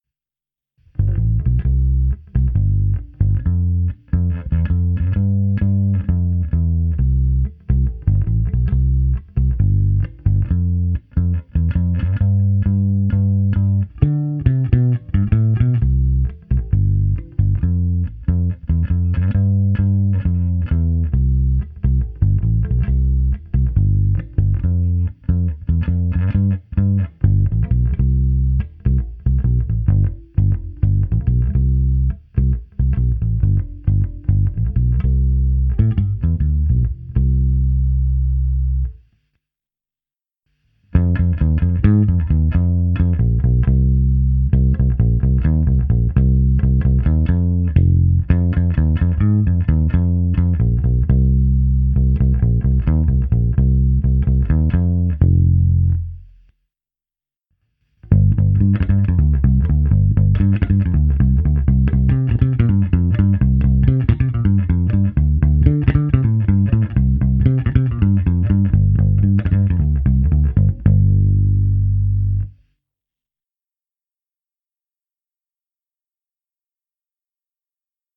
Zvuk asi mnohým sedět nebude, má výrazný kontrabasový charakter, kratší sustain, ale jinak je zamilováníhodný.
Pro představu zvuku přes aparát jsem předchozí nahrávku protáhl softwarem AmpliTube 4 se zapnutou simulací basového aparátu snímaného mikrofony.
Bonusová ukázka přes AmpliTube